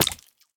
sounds / entity / fish / hurt2.ogg